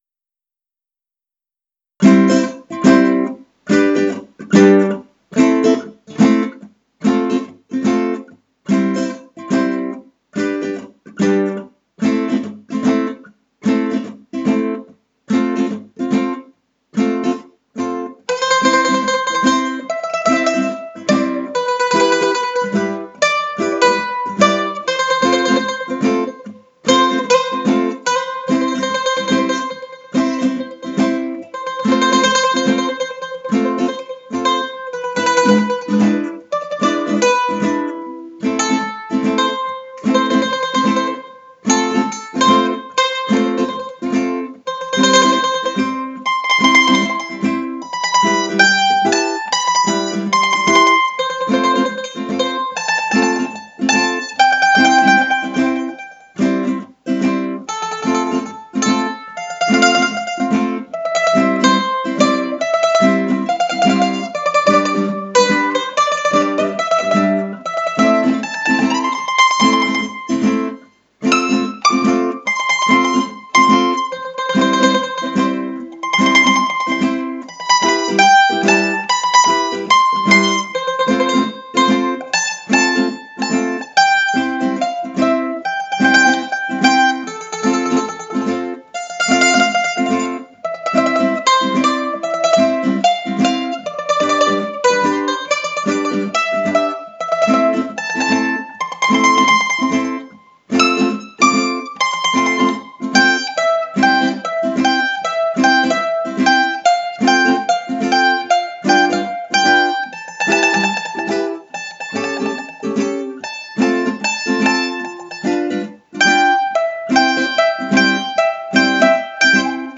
Cerimonia Civile